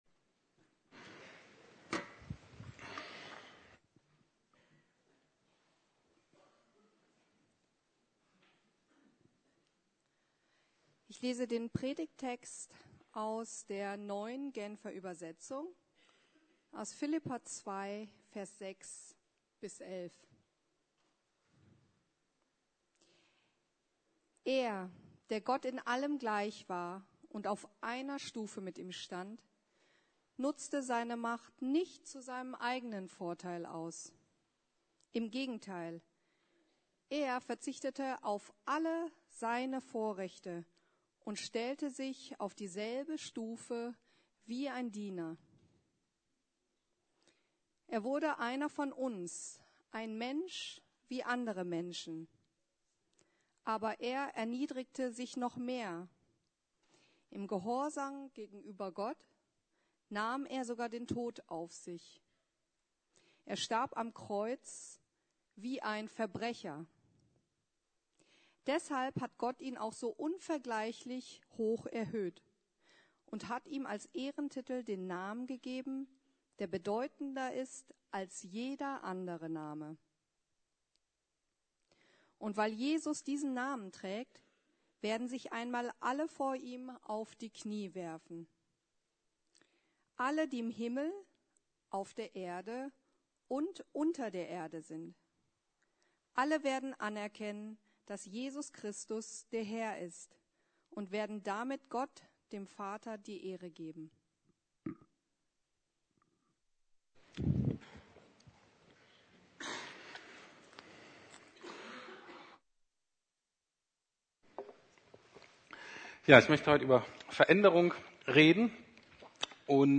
Veränderung: Not - oder notwendig? ~ Predigten der LUKAS GEMEINDE Podcast